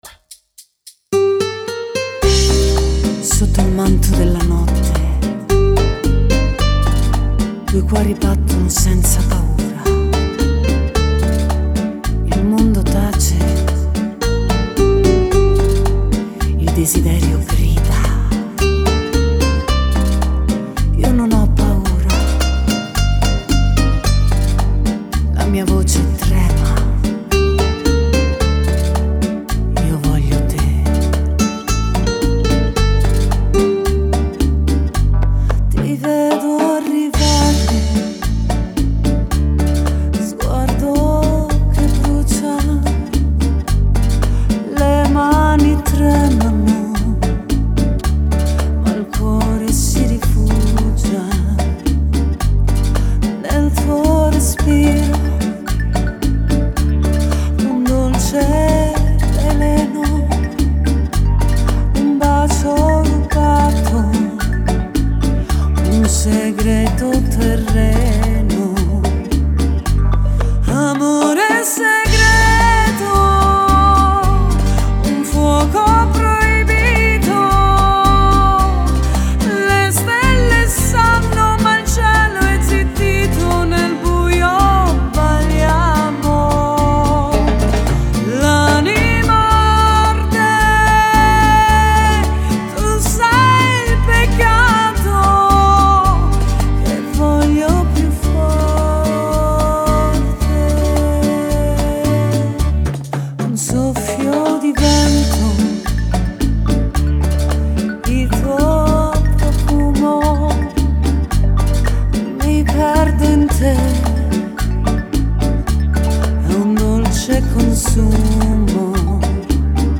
(Rumba)